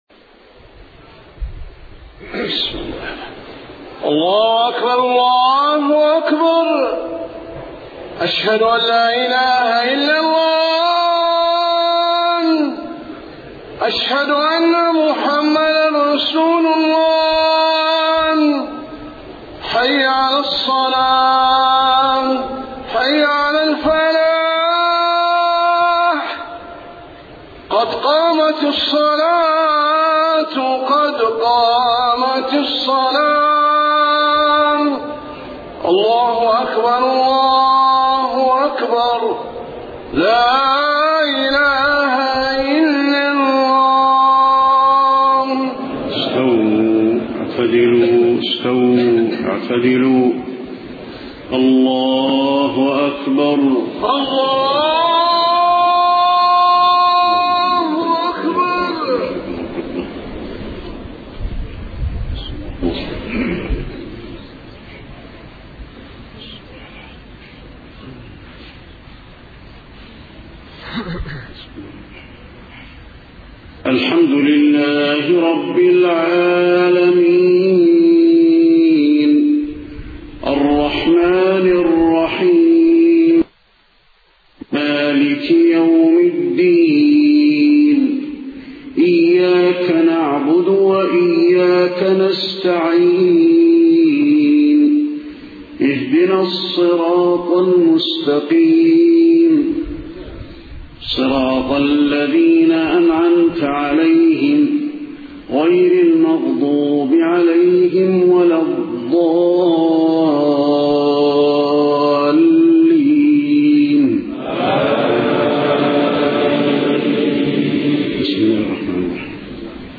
صلاة المغرب 27 صفر 1431هـ سورتي العصر و الكوثر > 1431 🕌 > الفروض - تلاوات الحرمين